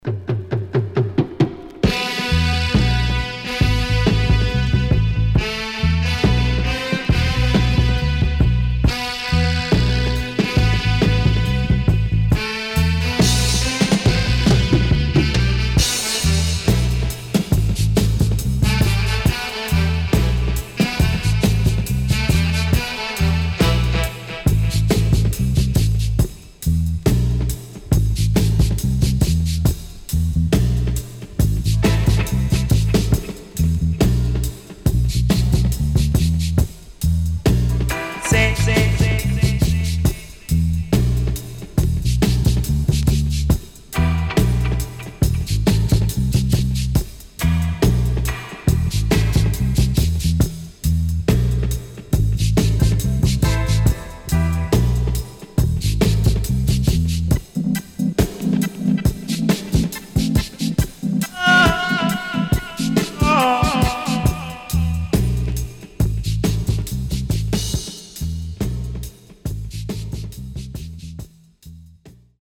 80's 渋Roots Vocal & Dubwise.W-Side Good
SIDE A:盤質は良好です。